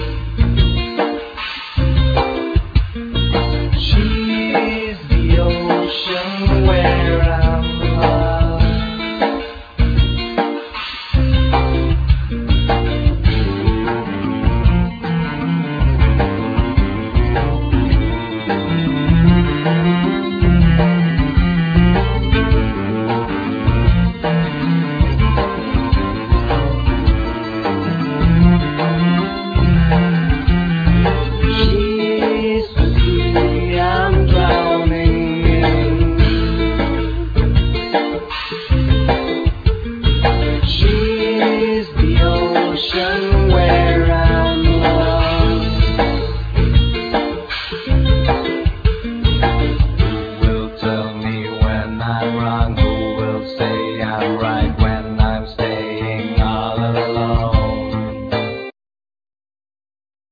Vocals,Violin,Guitar,Bass
Guitars,Bass,Vocals
Cello,Vocals
Drums,Percussions